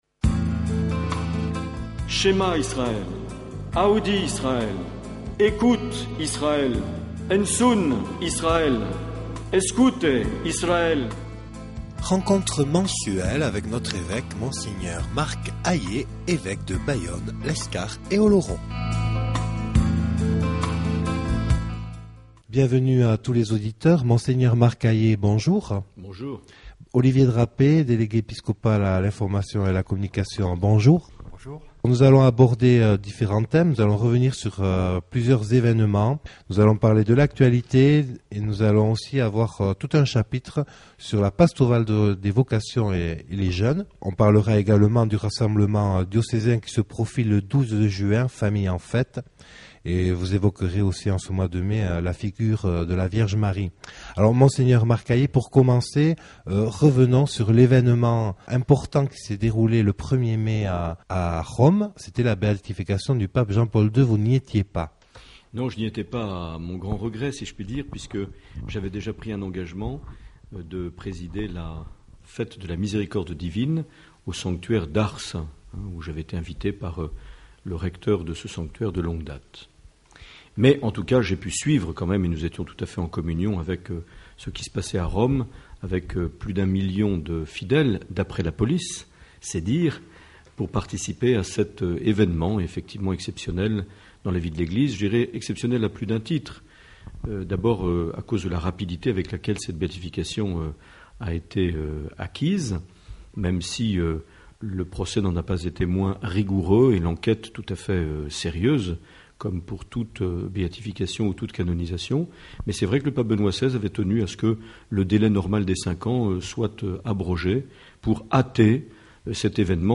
Les entretiens
Une émission présentée par Monseigneur Marc Aillet